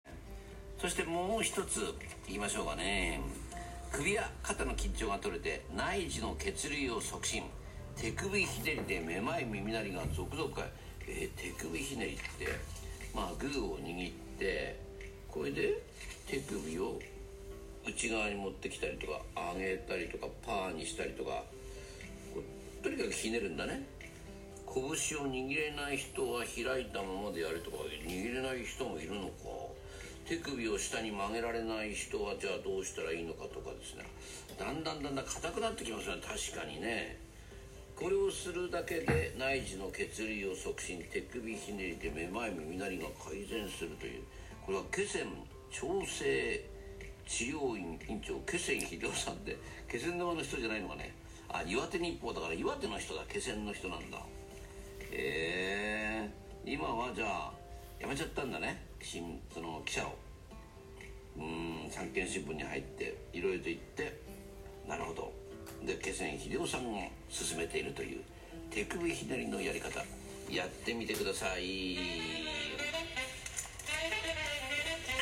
当院が紹介されました（2022年9月5日放送）